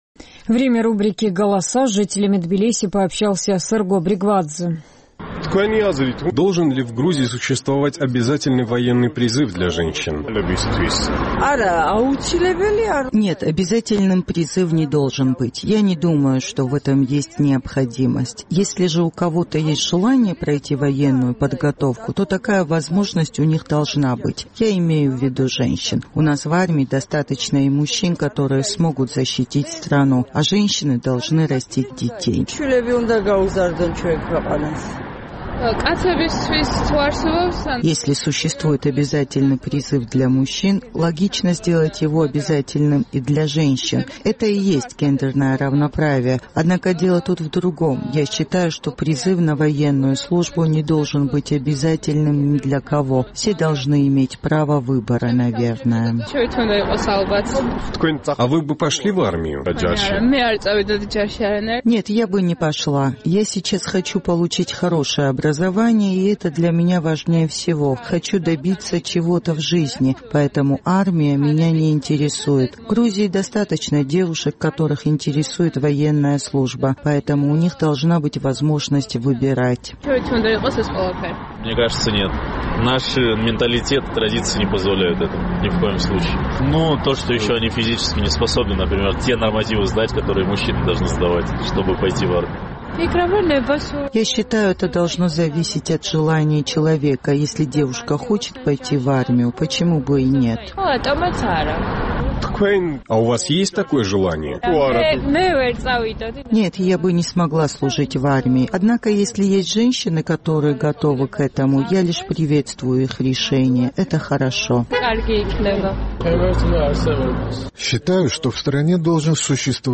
Наш тбилисский корреспондент узнавал, как отнеслись к этой инициативе жители столицы.